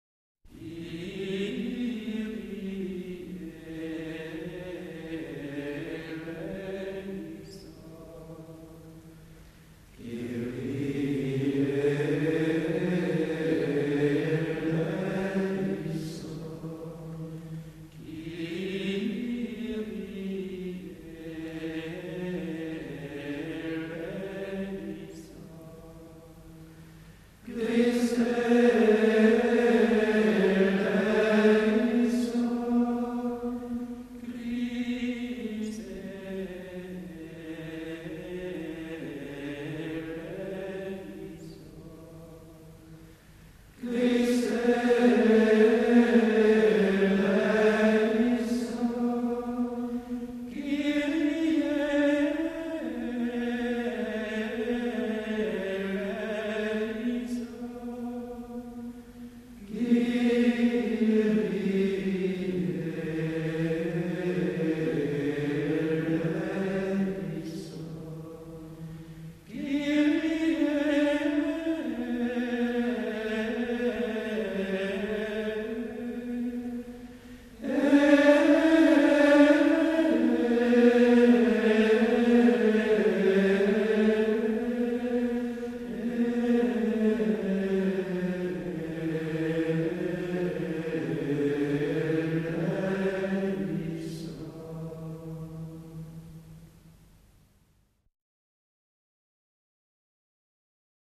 C’est un 1er mode, et il suit le même schéma que le précédent : aba, cdc, ede’.
Les finales de chaque invocation s’achèvent toutes de façon syllabique, sur les trois dernières syllabes de eléison, avec un épisème sur l’accent, mais selon deux versions différentes : on a six fois la formule Fa-Ré-Ré, et trois fois la formule Sol-La-La.
Le balancement entre ces deux Kyrie plus aigus et le second, au milieu plus grave, a quelque chose de très berceur, de très doux et en même temps oriente l’intensité vers la suite.
L’accent de Kyrie est pris au posé, il est chanté de manière très ferme, et en plein élan.
L’avant dernier Kyrie nous fait retrouver la formule du second Christe, plus calme, plus doux, mais toujours très ferme.
Ce beau Kyrie marial contient dans ses neumes et sa ligne mélodique une alternance émouvante de supplication, de confiance, d’humilité, le tout enveloppé dans la paix du 1er mode.